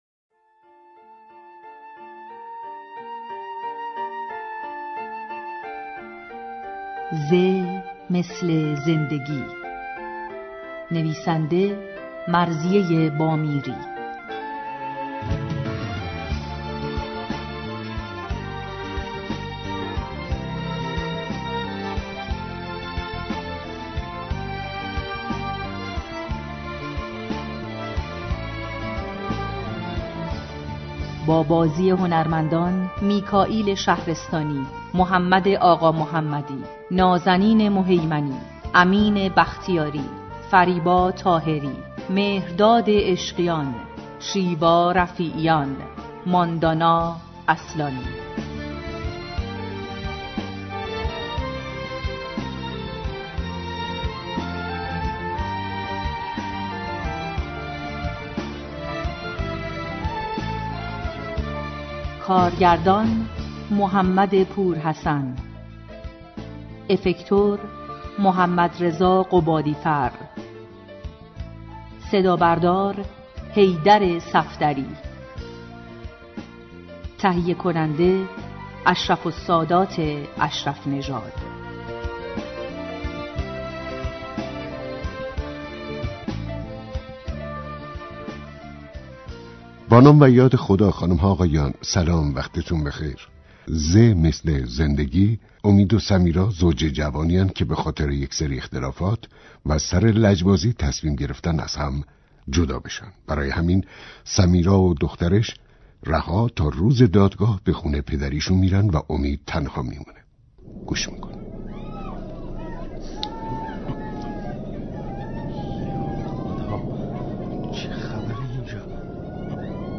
پخش نمایش رادیویی